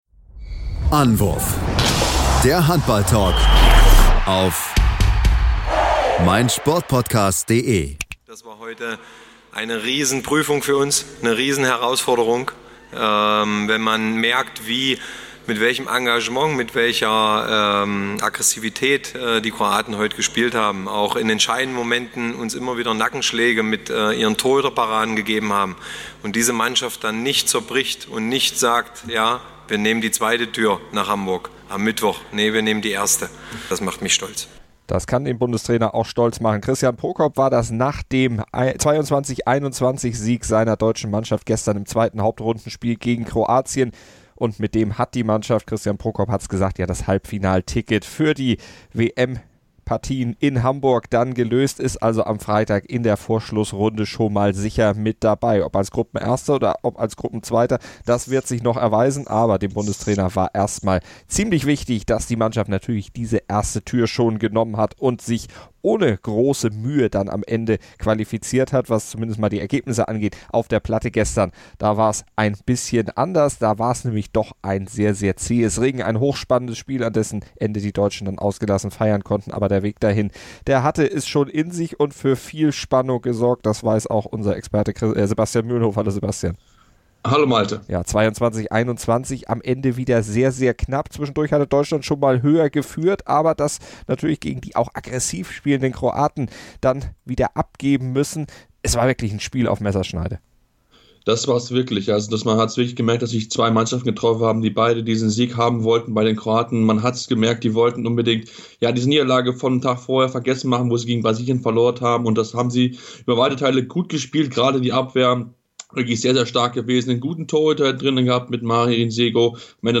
Matchwinner Fabian Wiede, Hendrik Pekeler, Uwe Gensheimer, Jannik Kohlbacher und Matthias Musche sowie der Bundestrainer fanden den Weg an unser Mikro.